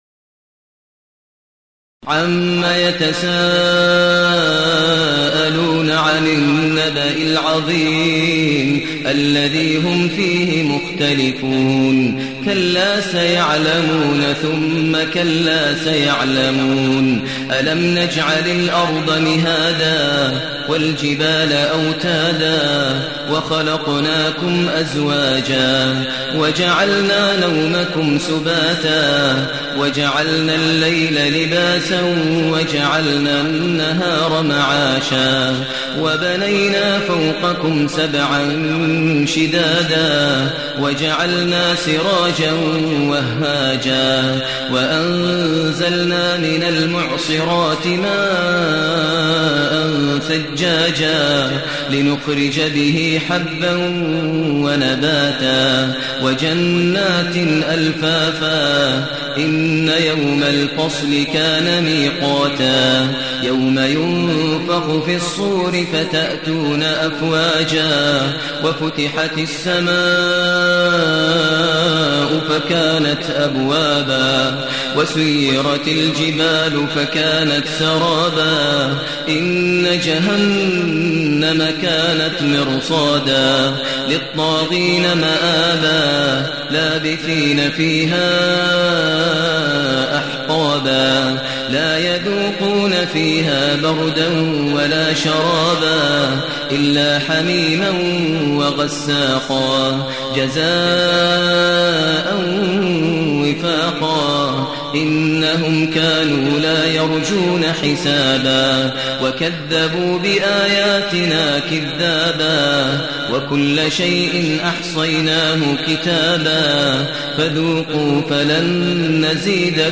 المكان: المسجد النبوي الشيخ: فضيلة الشيخ ماهر المعيقلي فضيلة الشيخ ماهر المعيقلي النبأ